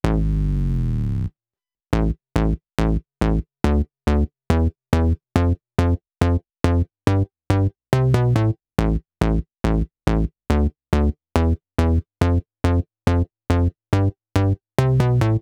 VTDS2 Song Kit 07 Pitched Mr Big Boom Bass.wav